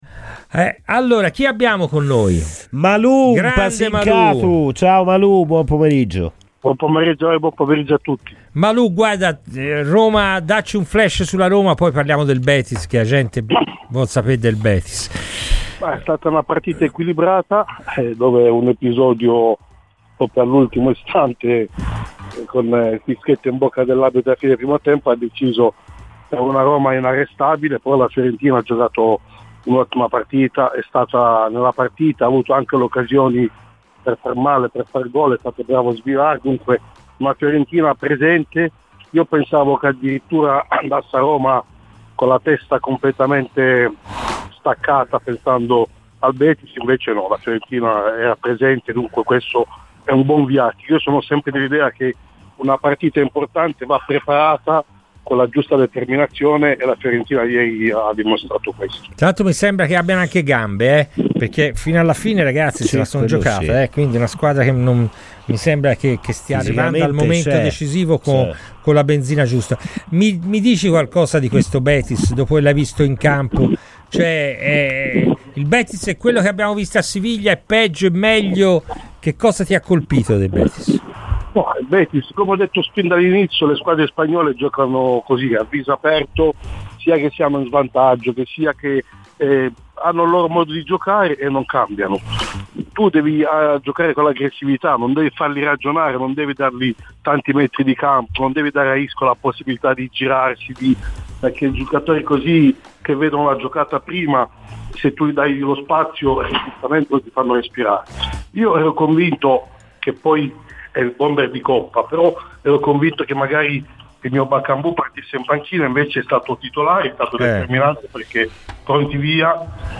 è intervenuto a Radio FirenzeViola durante la trasmissione "Palla al Centro"